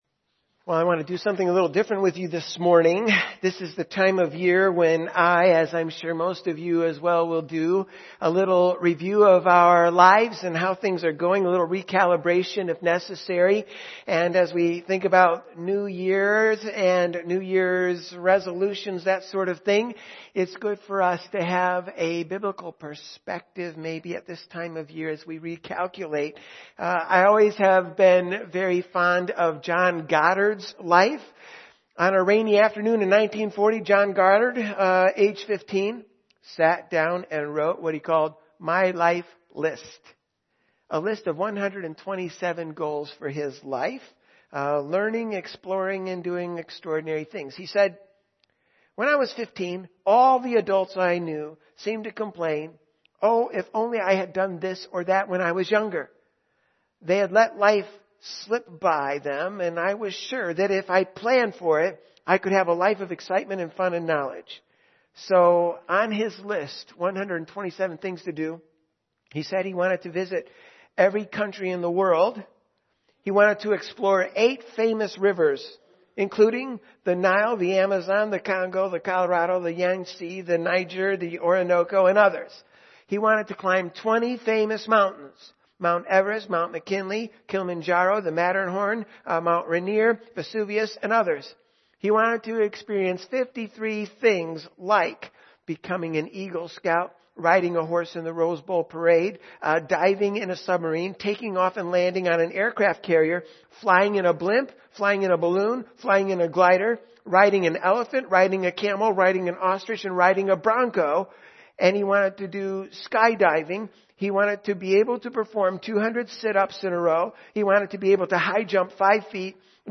Morning Service
Audio Sermons